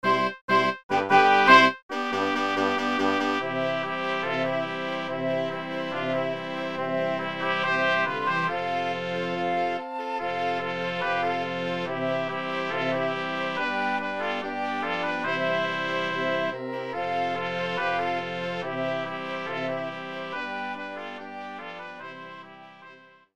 Biesiadne , ludowe